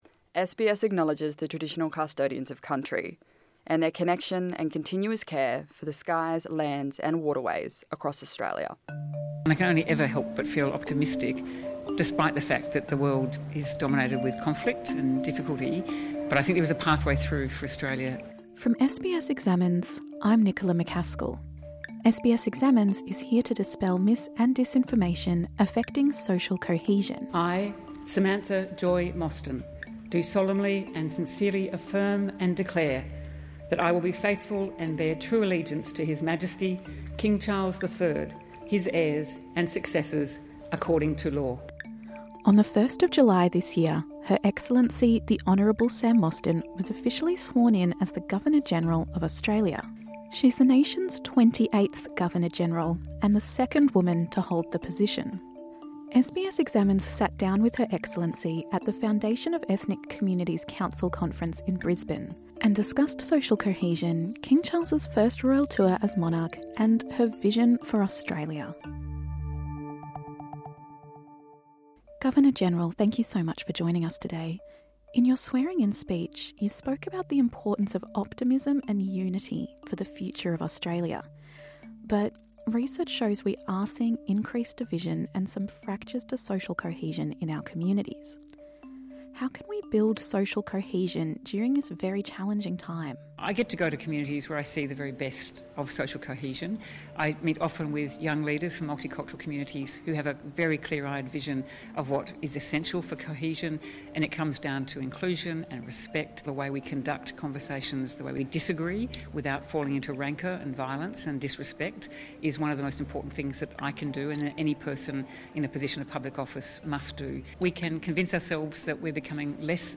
In an interview with SBS Examines, Her Excellency the Honourable Sam Mostyn shared her thoughts on social cohesion, optimism, and the role of the monarchy.